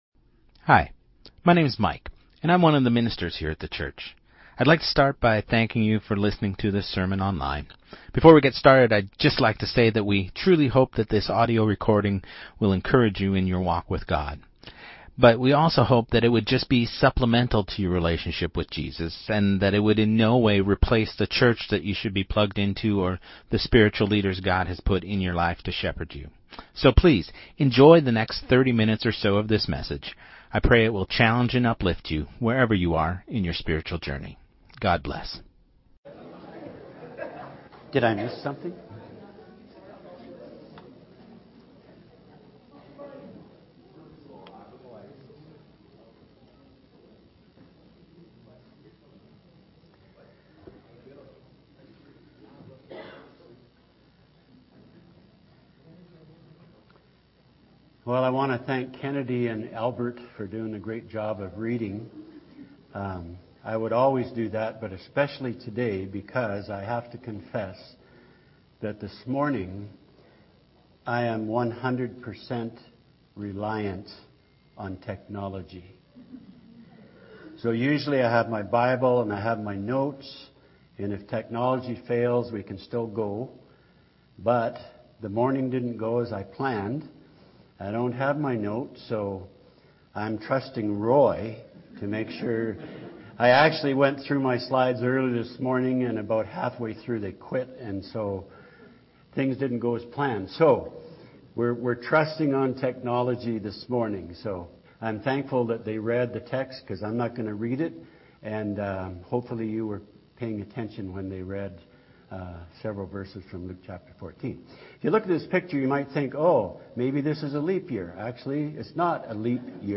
Sermon2026-01-04